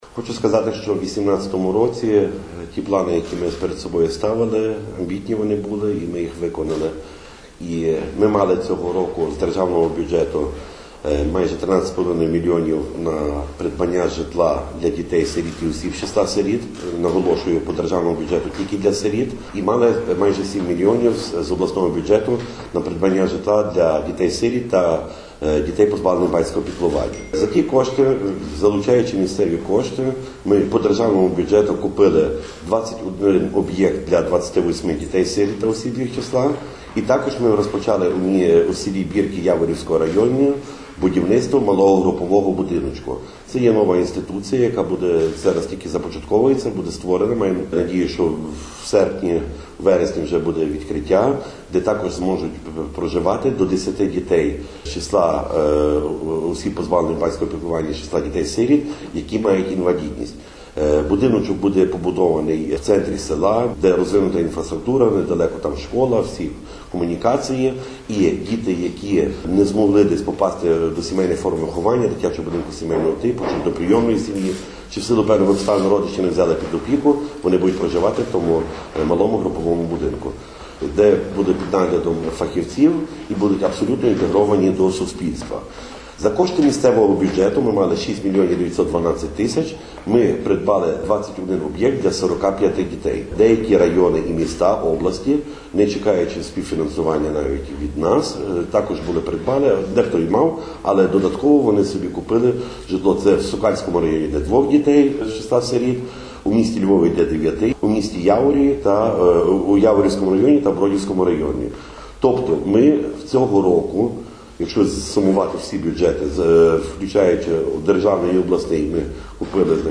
Про це повідомив сьогодні, 10 січня, під час брифінгу начальник Служби у справах дітей Львівської ОДА Володимир Лис.